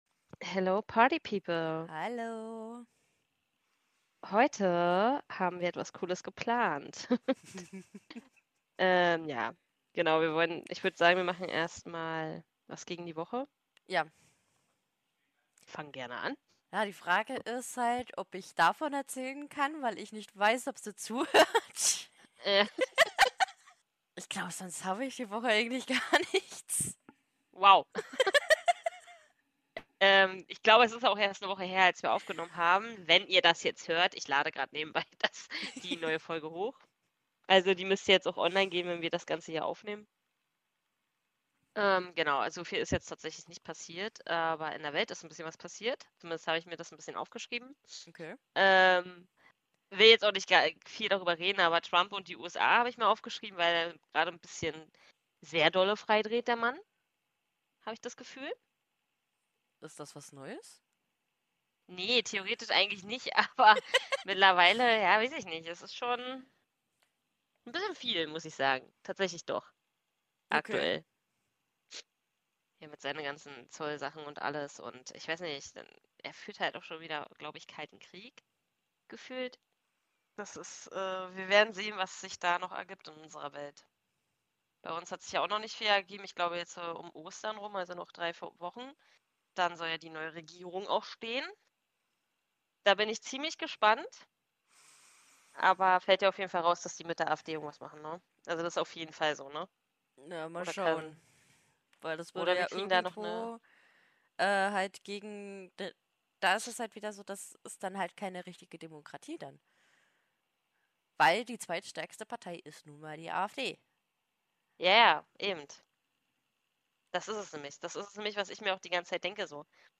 Es wird gelacht, geraten und auch mal gestaunt – denn einige Geschichten klingen zu verrückt, um wahr zu sein (Spoiler: Manche sind es trotzdem).